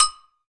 9LW AGOGO.wav